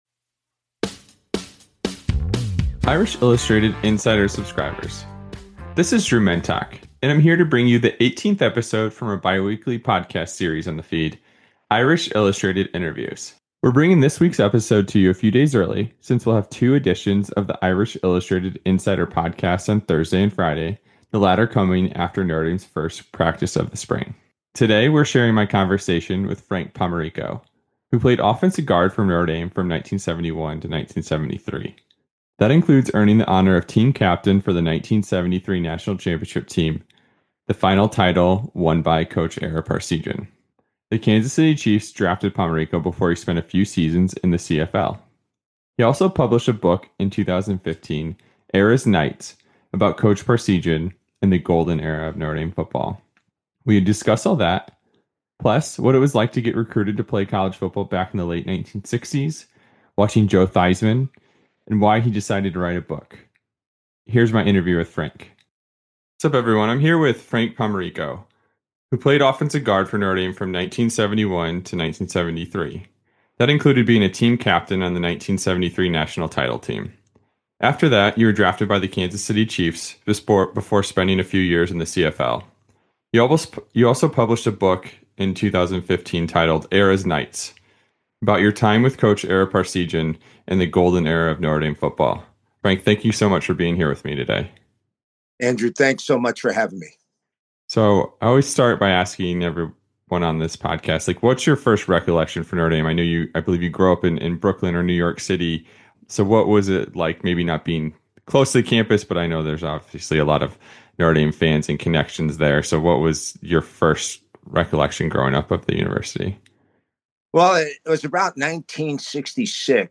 Irish Illustrated Interviews